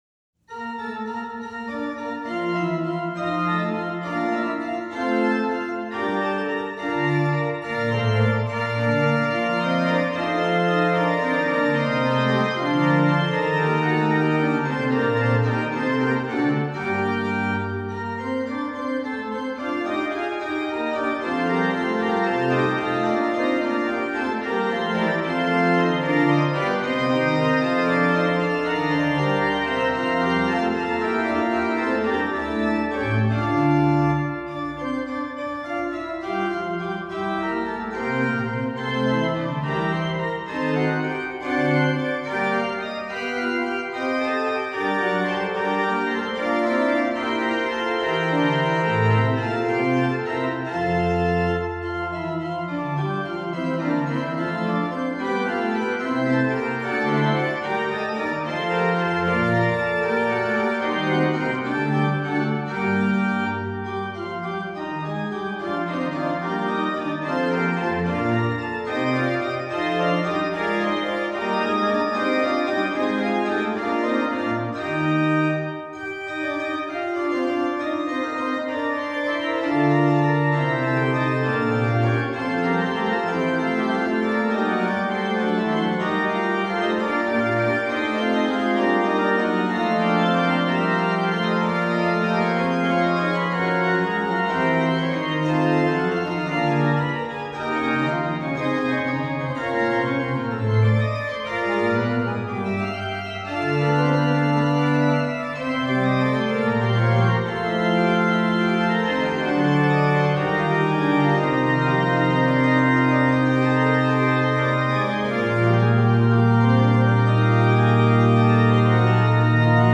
Subtitle   manualiter
Venue   1722 Gottfried Silbermann organ, Marienkirche, Rötha, Germany
Registration   Pr8, Oct4, Oct2, Cym